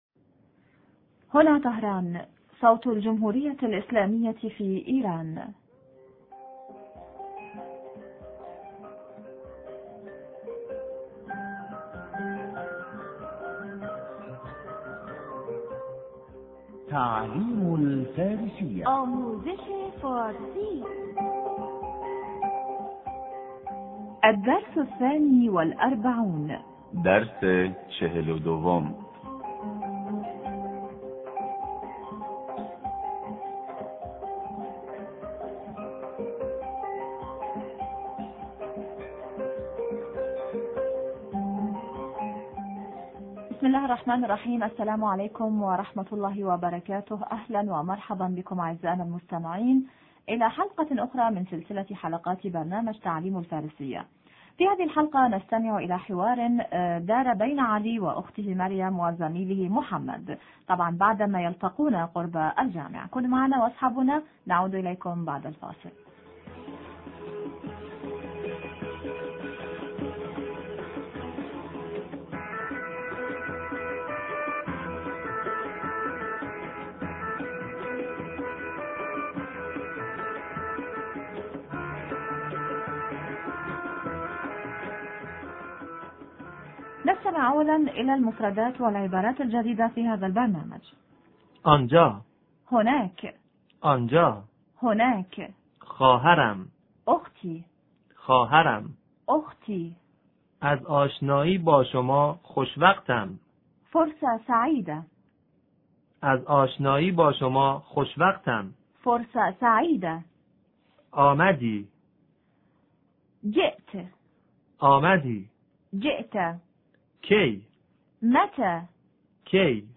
إذاعة طهران- تعلم الفارسية- حديث حول لقاء الصديقين